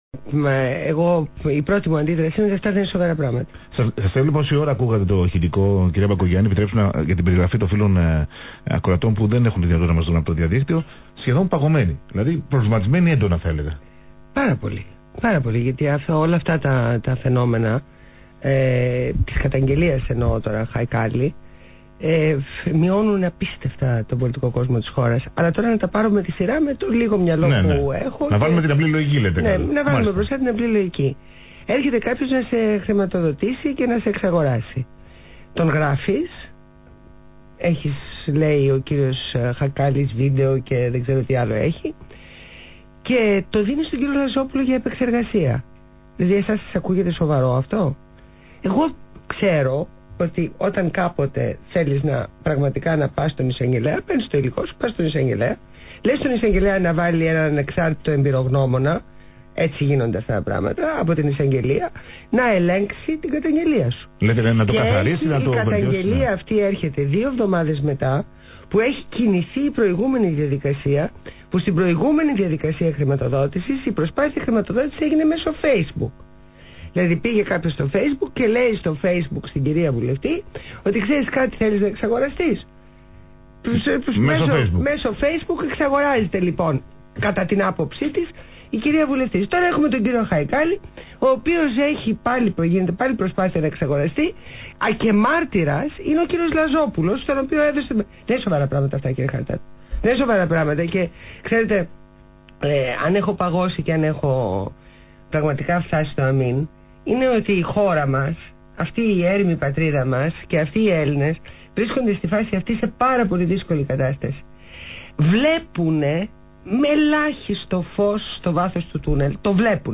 Συνέντευξη στο ραδιόφωνο του ALPHA